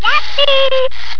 「ヤッピー！」（ハービー、a-bee.wav１３ｋｂ）
どう？やっぱり「ヤッピー！」って、言ってるよね？